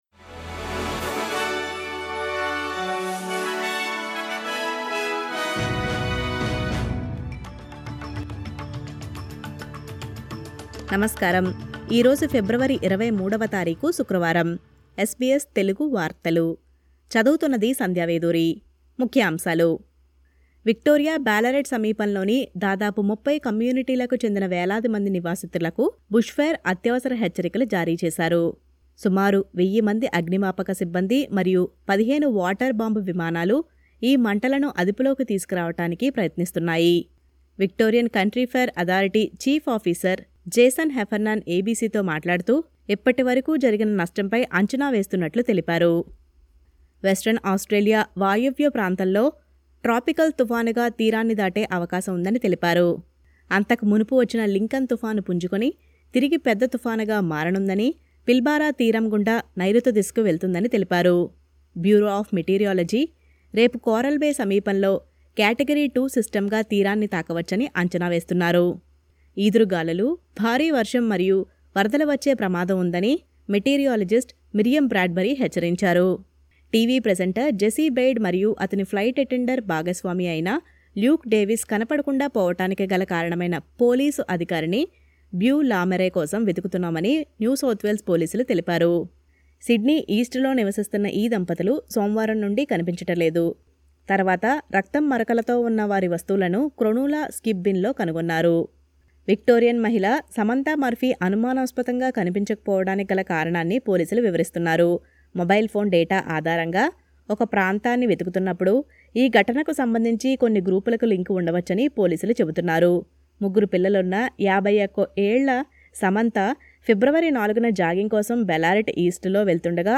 SBS తెలుగు వార్తలు